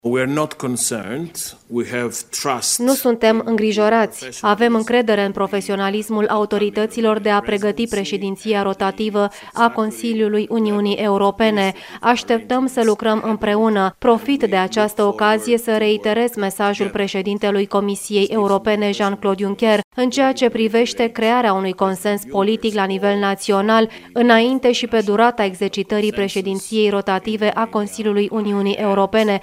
La nivelul Comisiei Europene nu există îngrijorări legate de preluarea de către România a mandatului de şase luni în fruntea Consiliului Uniunii Europene, potrivit purtătorului de cuvânt al executivului comunitar, Margaritis Schinas. Într-o conferinţă de presă, la Bruxelles, el a reamintit mesajul preşedintelui Comisiei Europene privind crearea unui consens politic naţional pe durata preşedinţei Consiliului ce va fi preluată de România la 1 ianuarie: